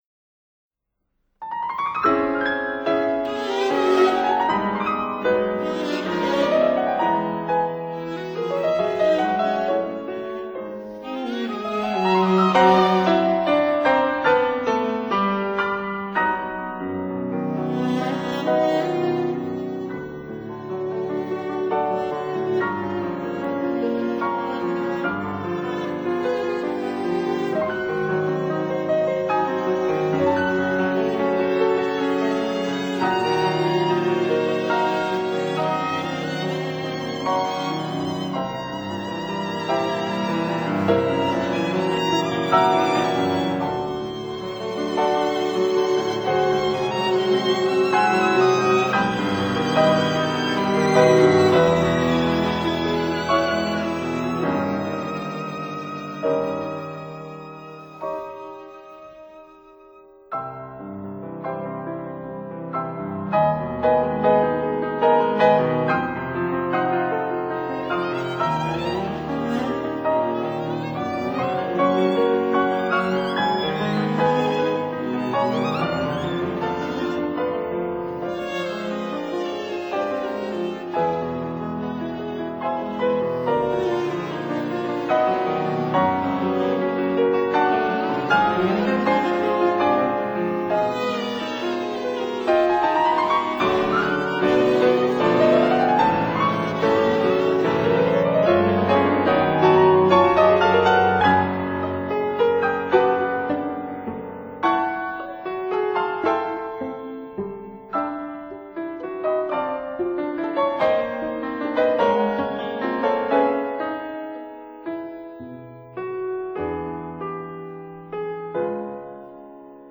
violin
cello
piano Date